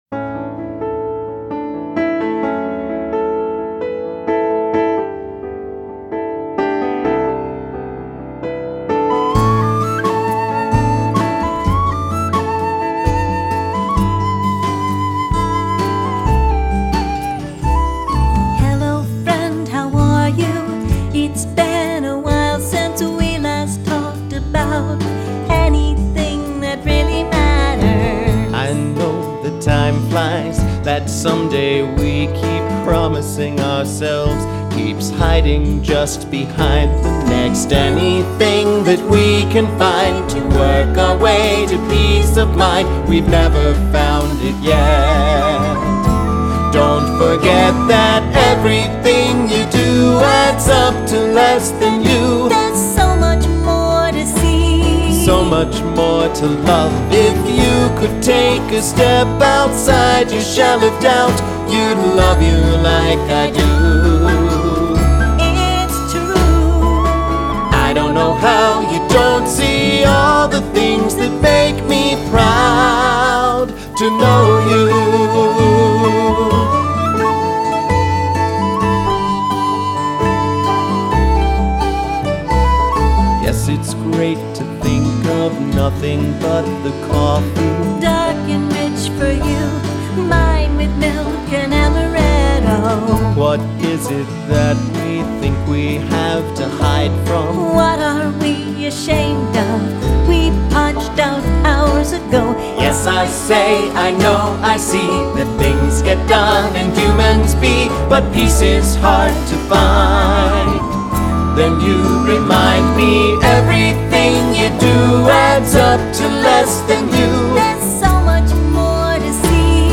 A solo arrangement